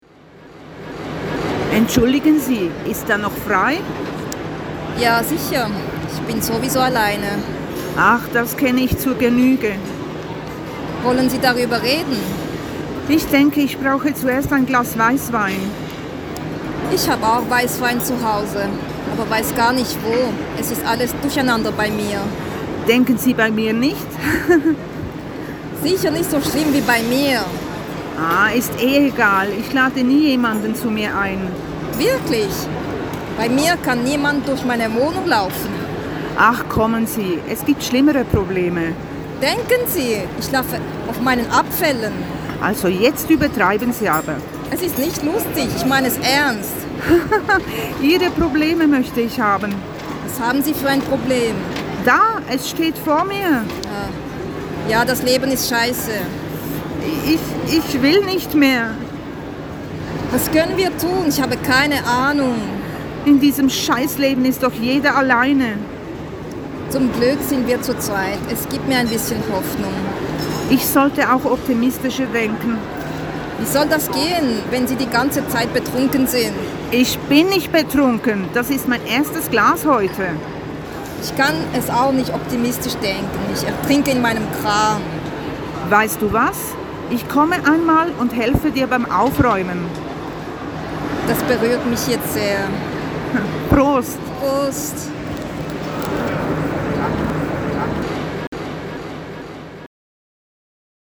Die Hörbeiträge aus dem Tram